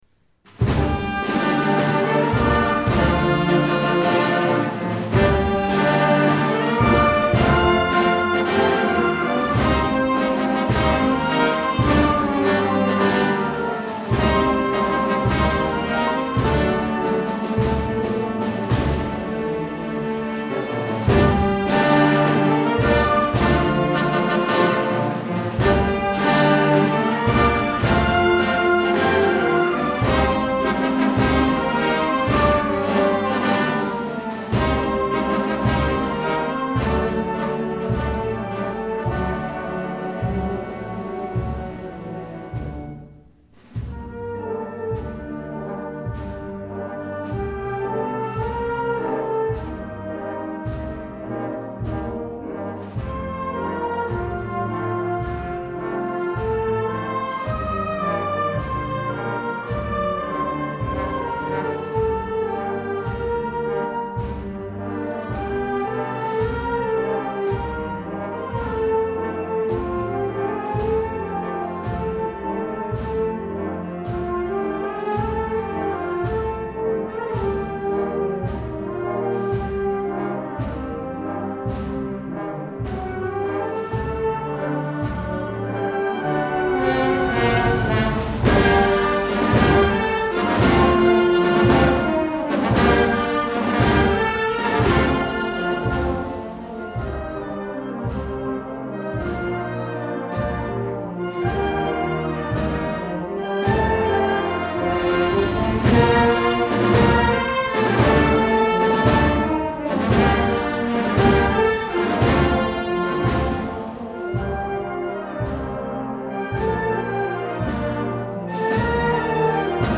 Concert de Setmana Santa - Auditori de Porreres.